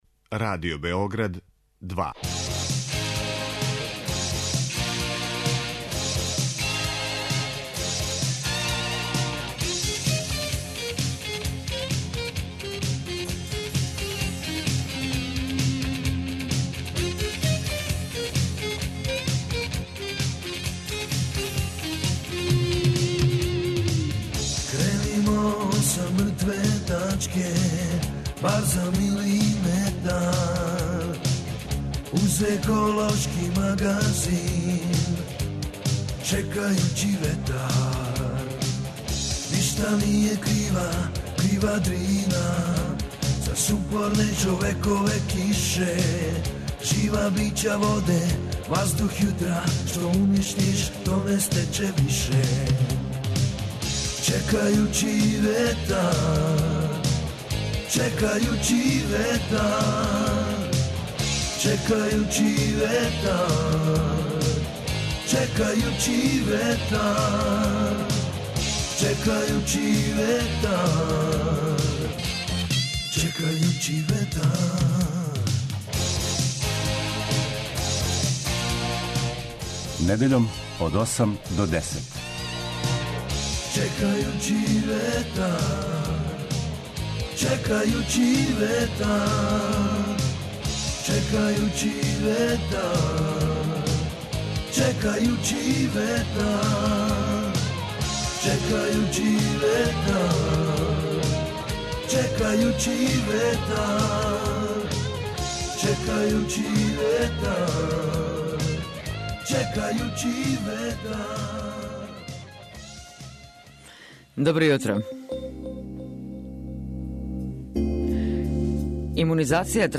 Еколошки магазин који се бави односом човека и животне средине, човека и природе.